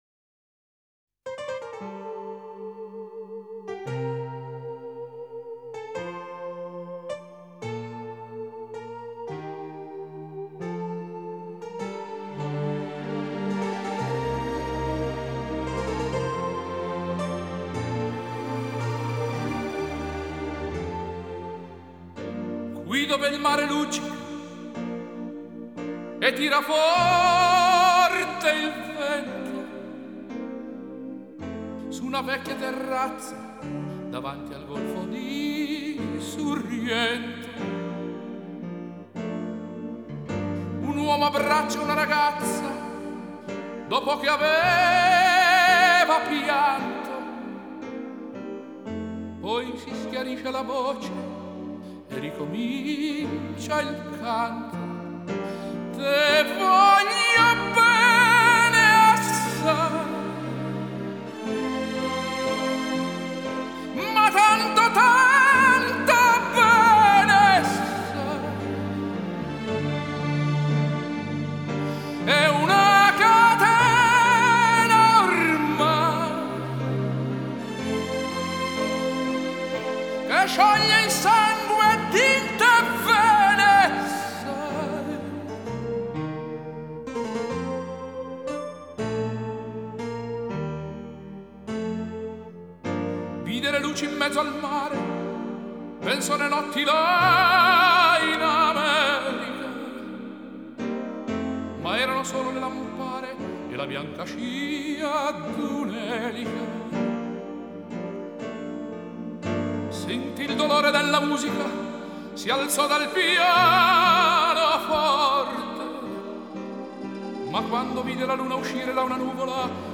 Сложная музыка